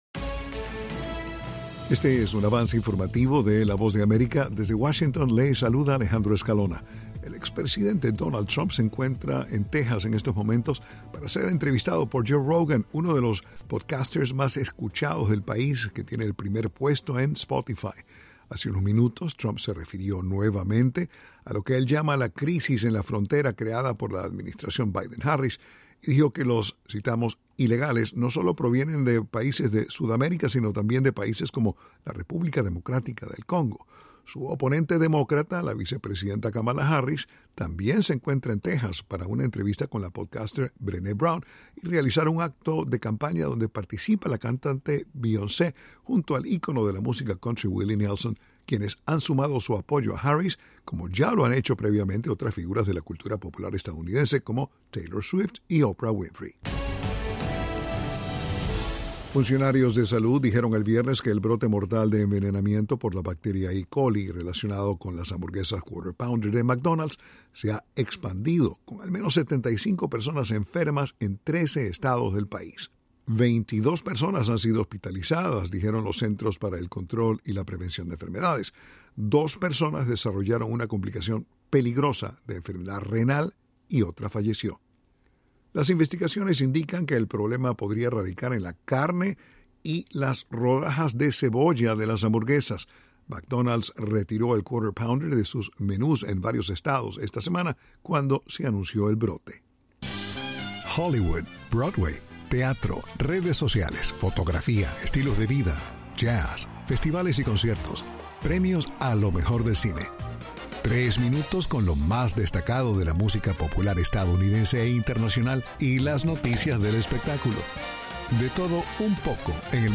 Avance Informativo
El siguiente es un avance informativo presentado por la Voz de América en Washington.